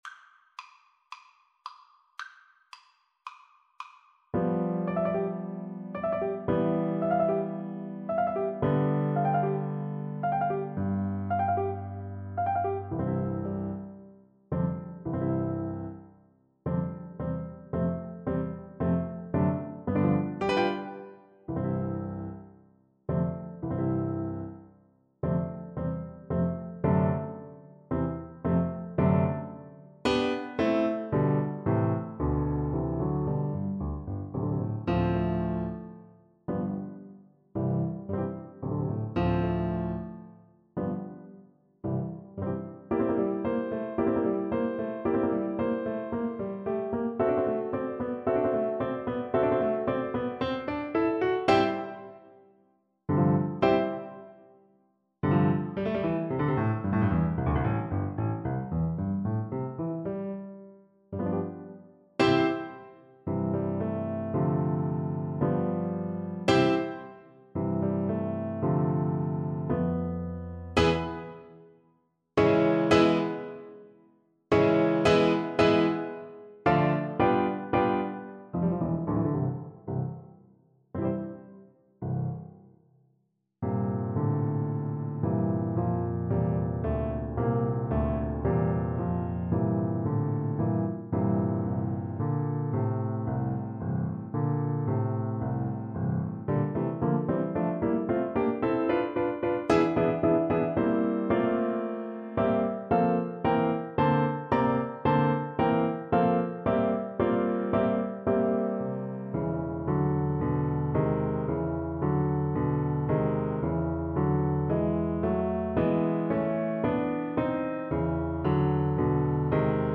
Allegretto scherzando =112
4/4 (View more 4/4 Music)
Classical (View more Classical Cello Music)